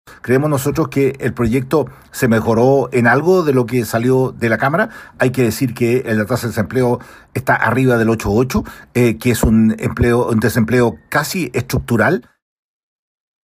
Su par de la Comisión de Hacienda, Miguel Mellado (RN), cuestionó duramente el modelo que sostiene el aumento.